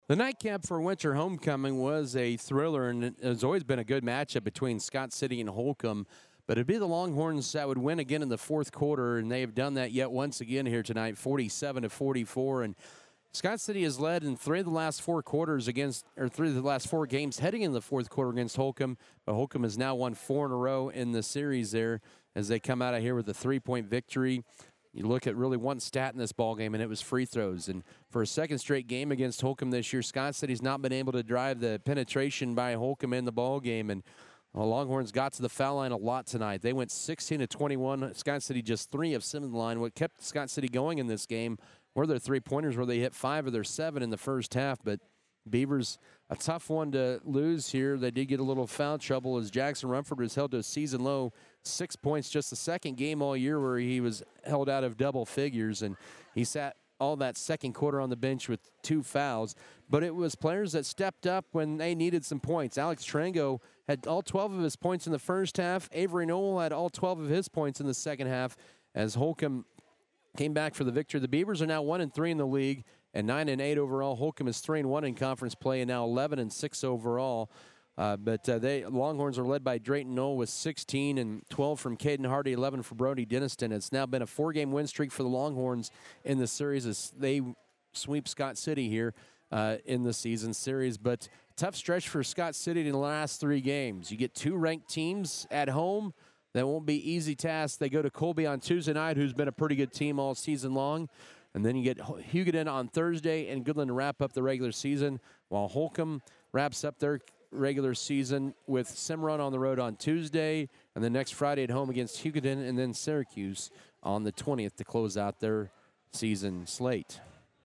Boys Audio Recap